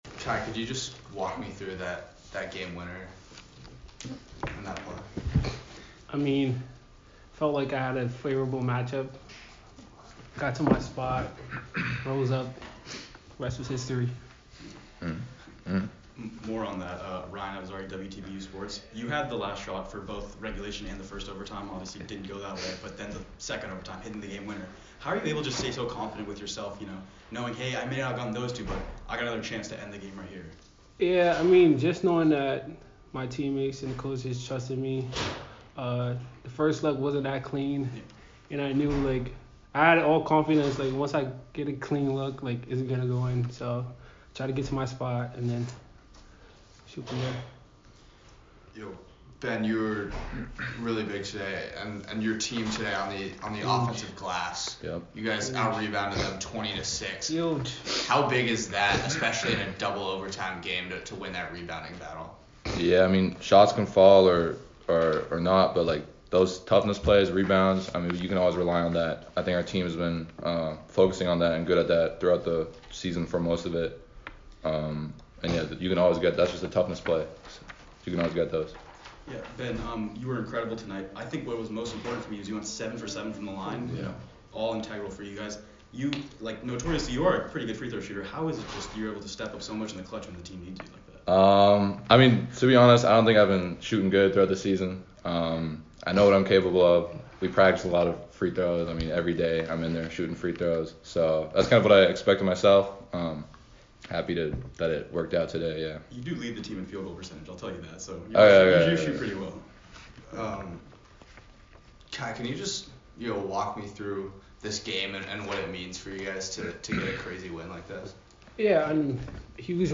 BU-Colgate-Postgame.mp3